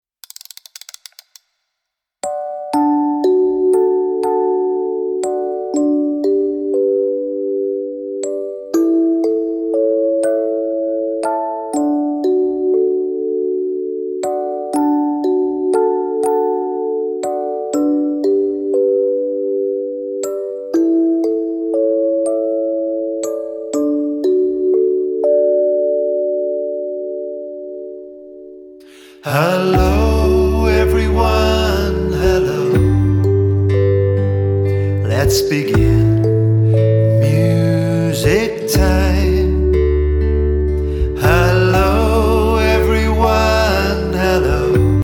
(a greeting song)